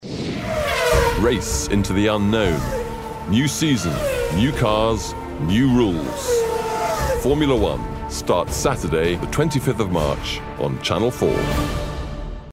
Captivate Your Audience with a Resonant British voice
TV PROMO
Formula 1 promo